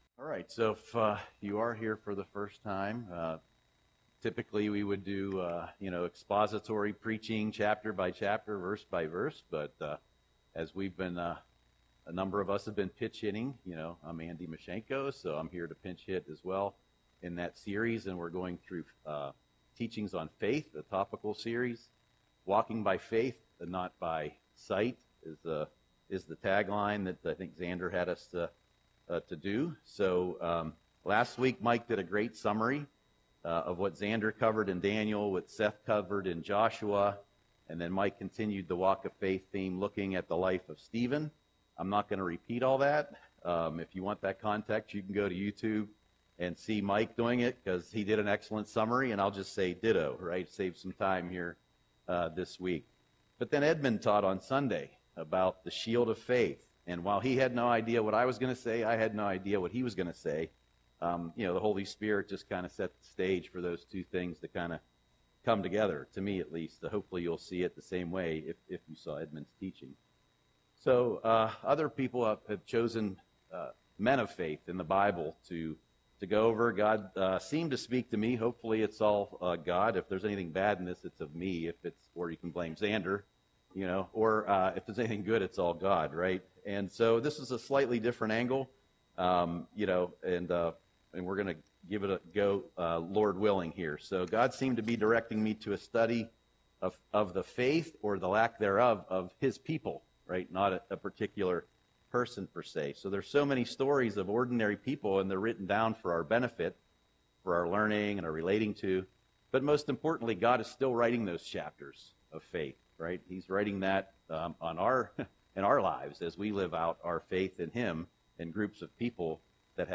Audio Sermon - April 2, 2025